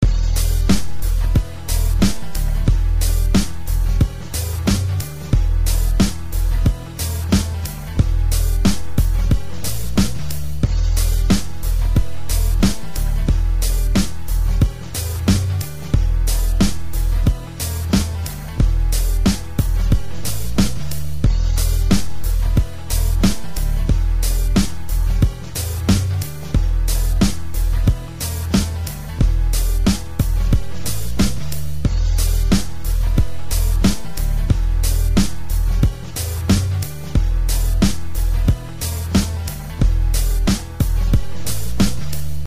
• Жанр: Хип-хоп